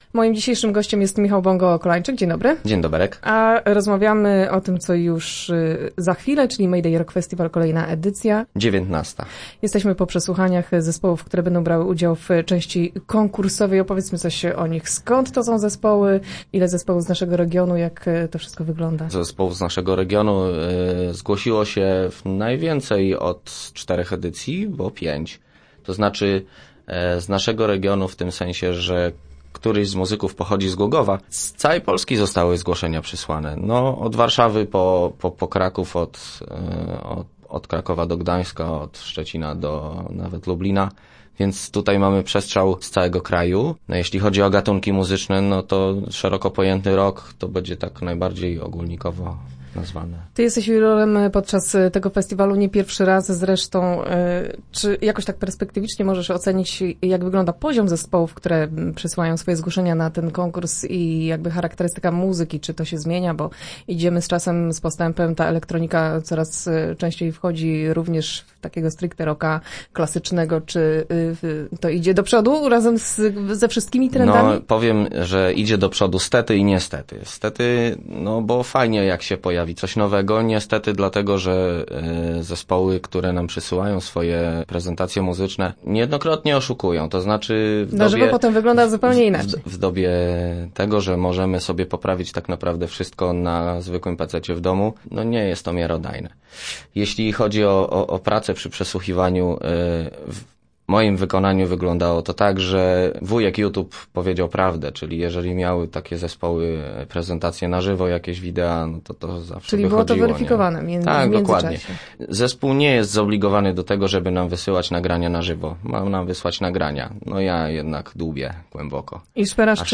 Start arrow Rozmowy Elki arrow Rockowa jesień w Głogowie